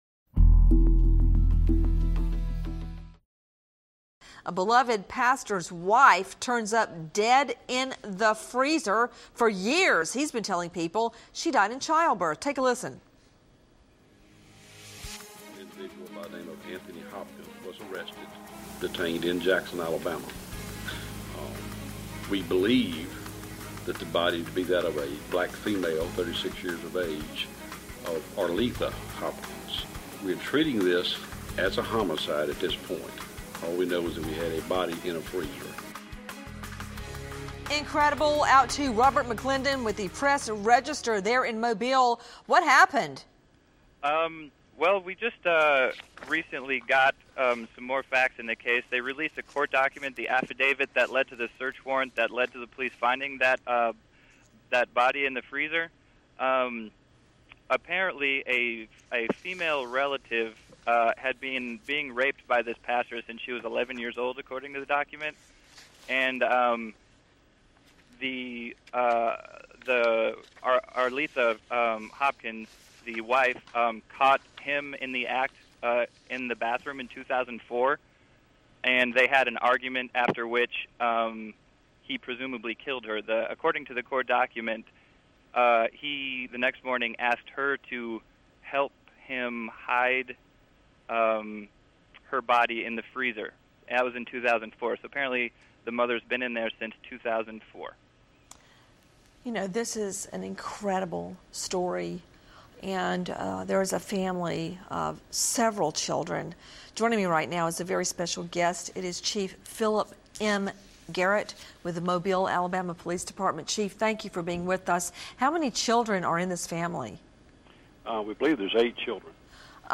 Christian Preacher Arrested (news clip)